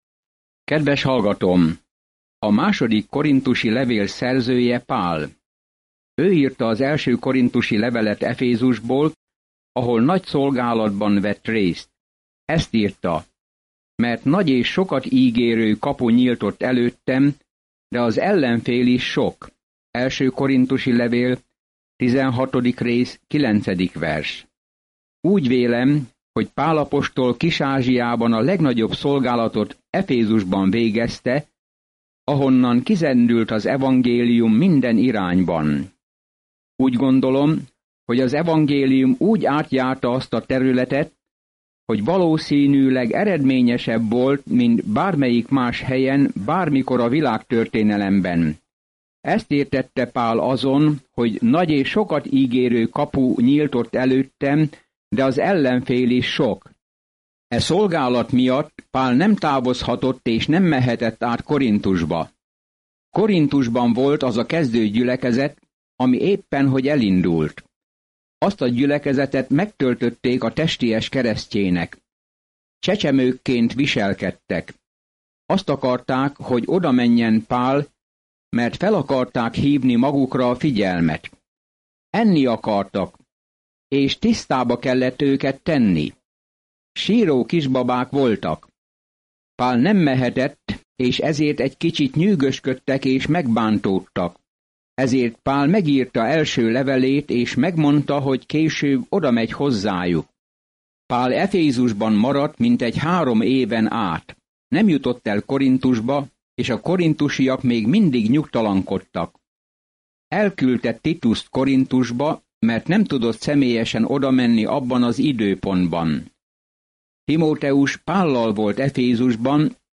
Szentírás 2Korinthus 1:1-4 Olvasóterv elkezdése Nap 2 A tervről A Krisztus testén belüli kapcsolatok örömeit emeli ki a korinthusiakhoz írt második levél, miközben hallgatod a hangos tanulmányt, és olvasol válogatott verseket Isten szavából. Napi utazás a 2 korinthusi levélben, miközben hallgatod a hangos tanulmányt, és olvasol válogatott verseket Isten szavából.